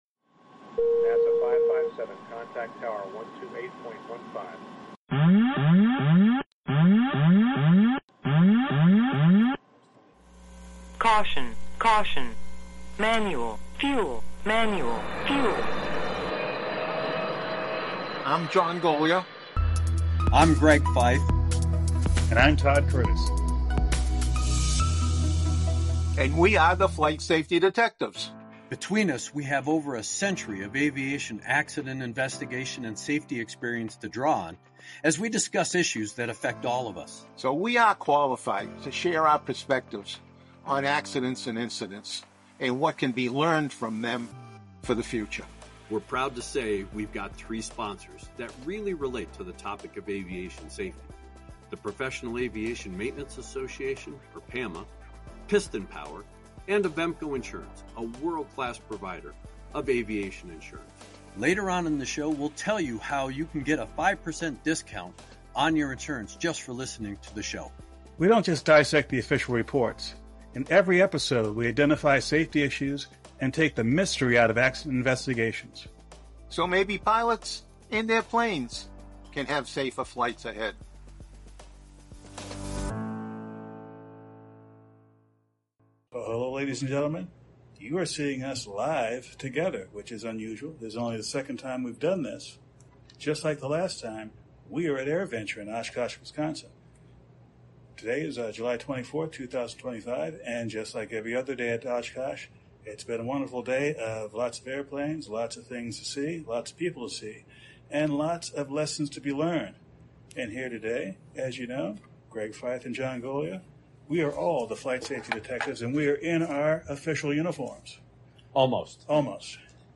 We bring you to AirVenture 2025 in Oshkosh, Wisconsin. This annual event attracts hundreds of thousands of aerospace professionals, aerospace enthusiasts, and their families for a unique aviation experience.
Live from Oshkosh! - Episode 285